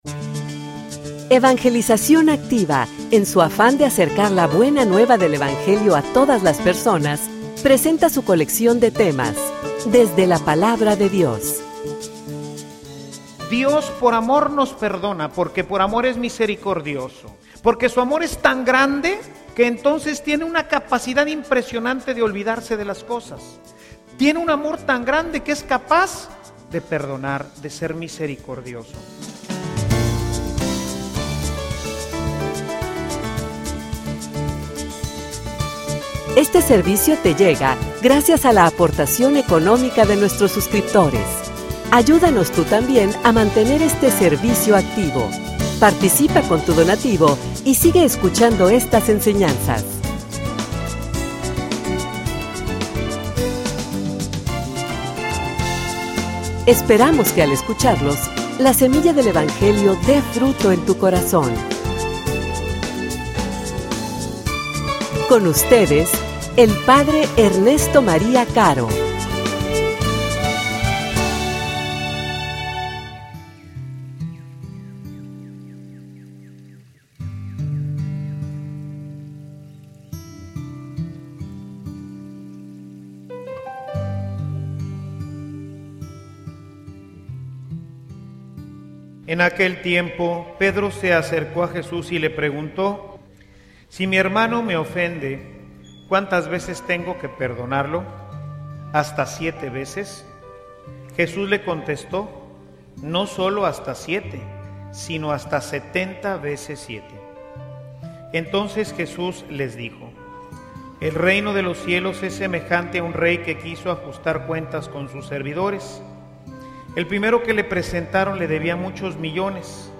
homilia_El_perdon_un_problema_de_memoria.mp3